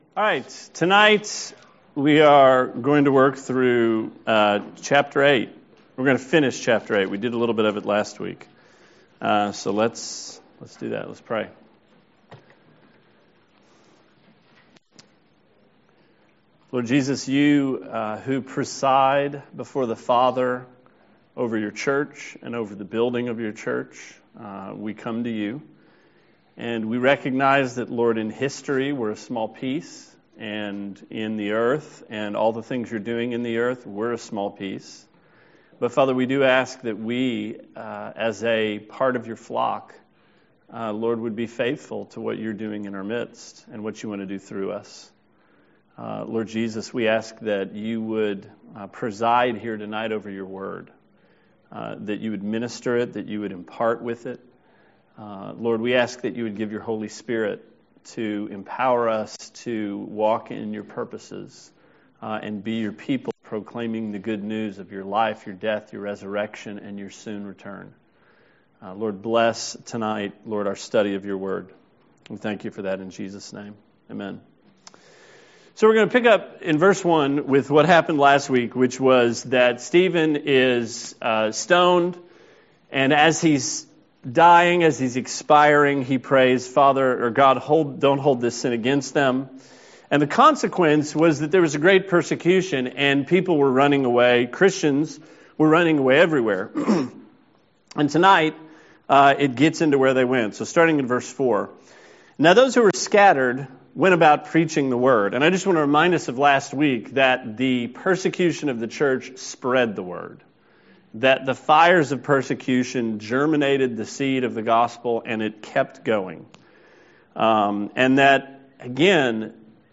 Sermon 5/13: Acts 8